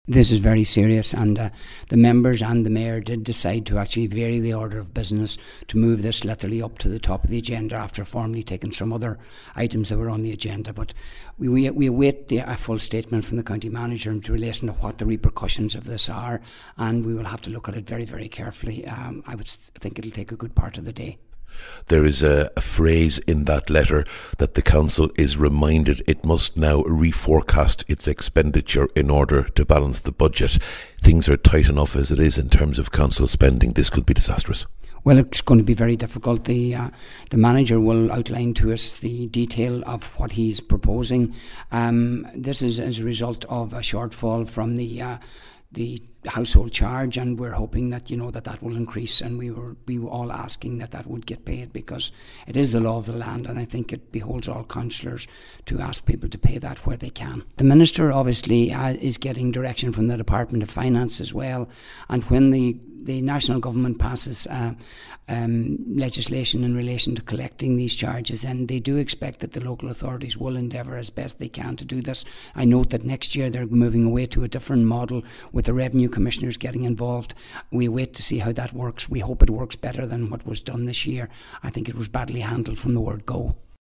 Former Fine Gael Councillor, Independent Terence Slowey says we should know the implications of the funding cut shortly: